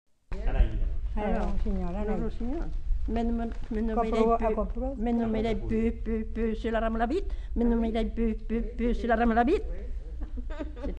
Lieu : Cancon
Genre : forme brève
Effectif : 1
Type de voix : voix de femme
Production du son : chanté
Classification : mimologisme